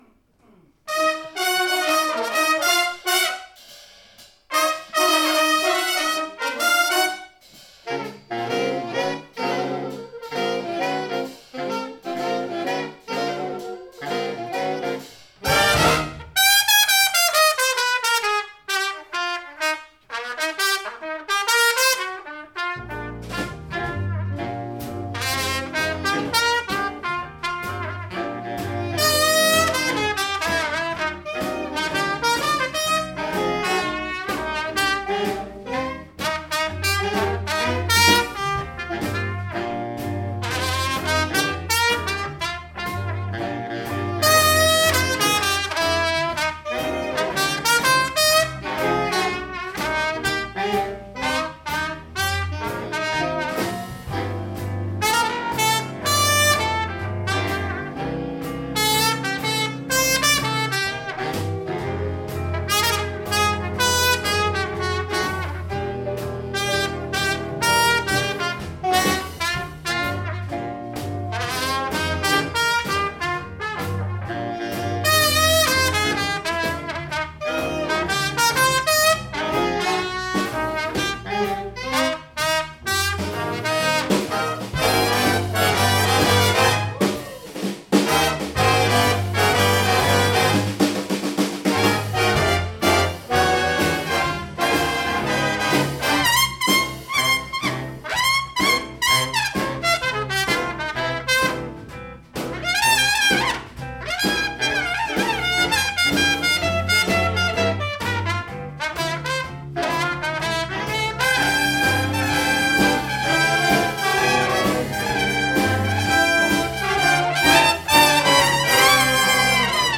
- Hayburner Big Band 26. oktober 2014
Introduktion af nummeret